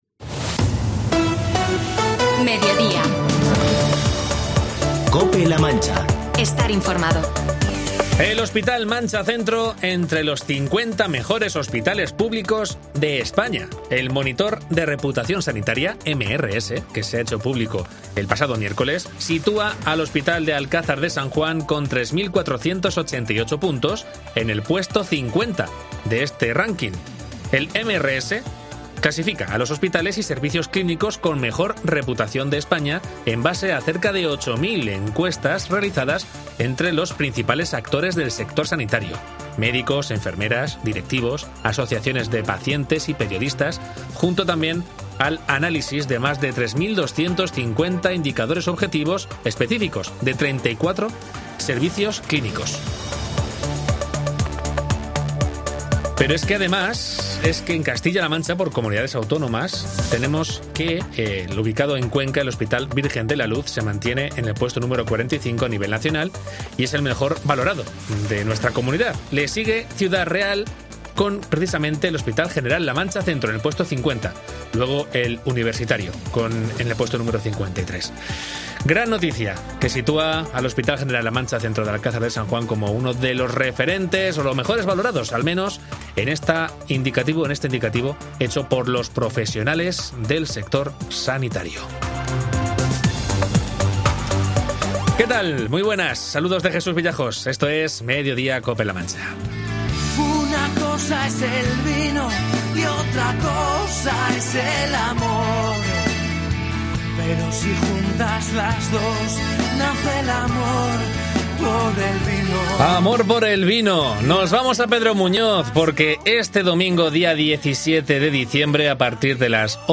Mediodía COPE en La Mancha, entrevista a Delfín Rosado, concejal de agricultura del Ayuntamiento de Pedro Muñoz